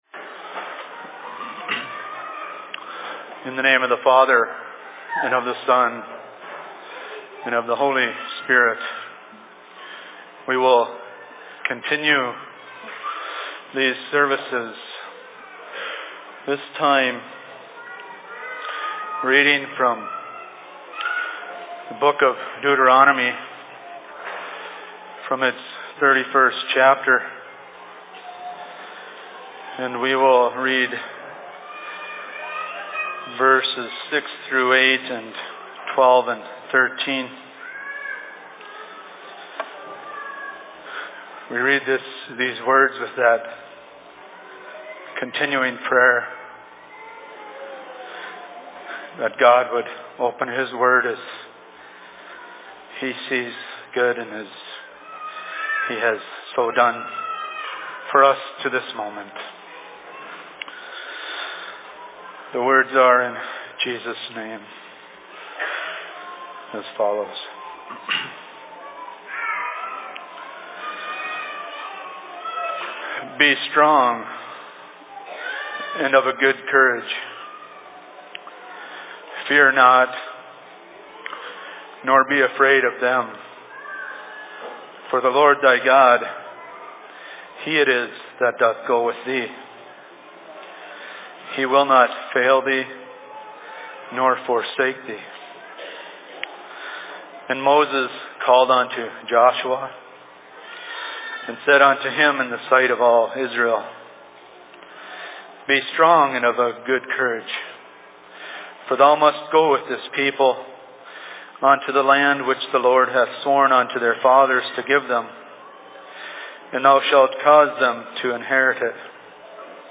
Sermon in Rockford 20.03.2016
Location: LLC Rockford